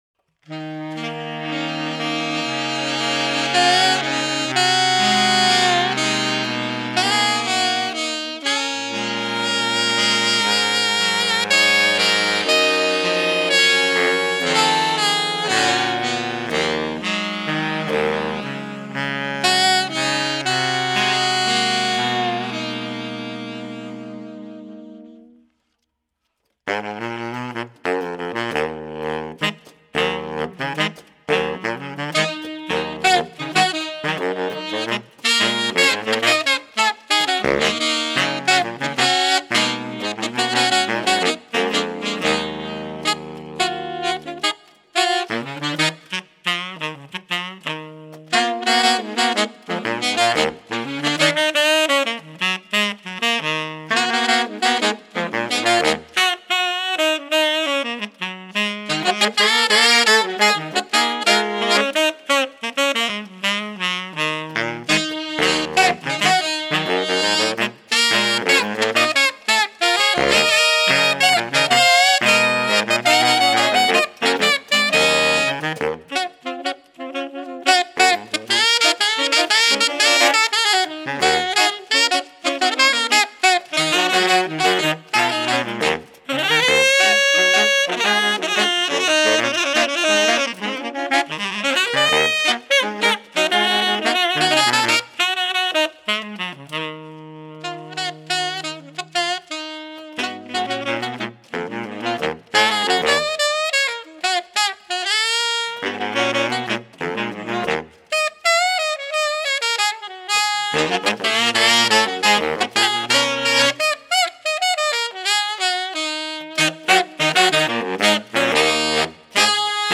Composer: Negro Spiritual
Voicing: Saxophone Quartet